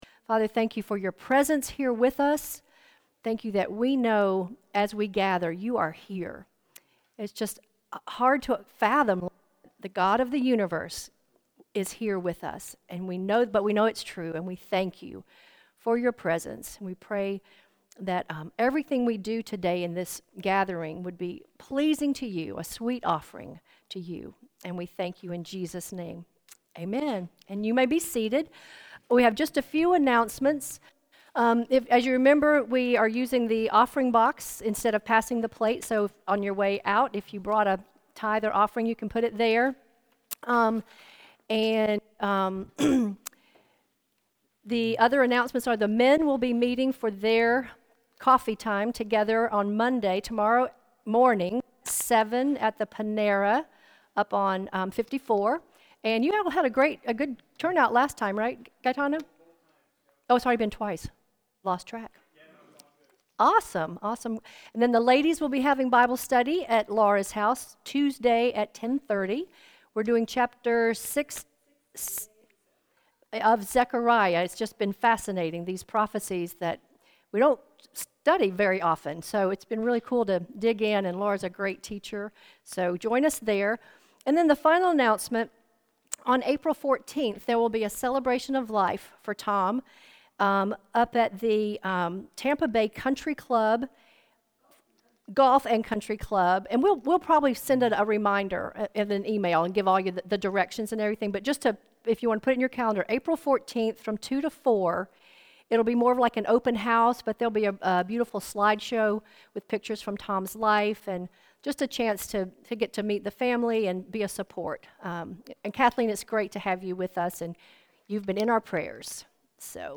Matthew 4:1-11 Service Type: Gathering Jesus was led up by the Spirit into the wilderness.